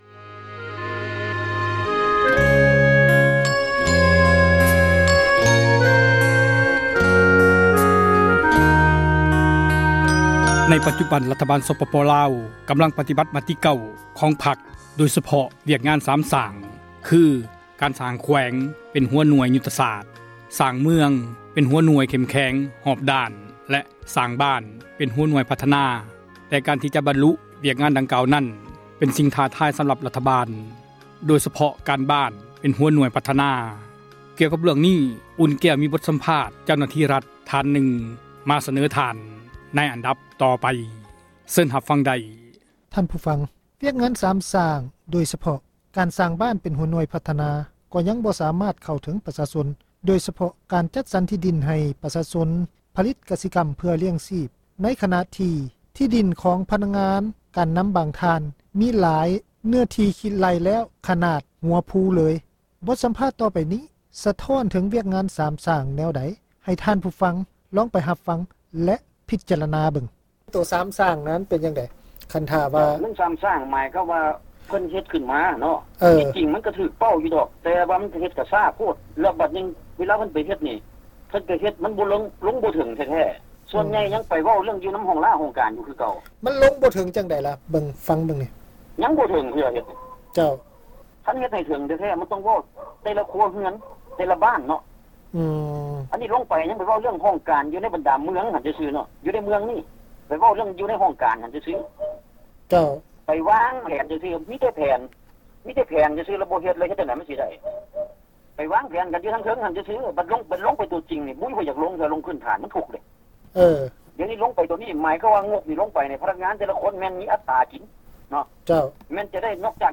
ສັມພາດຊາວບ້ານວຽກງານ ສາມ ສ້າງ